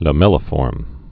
(lə-mĕlə-fôrm)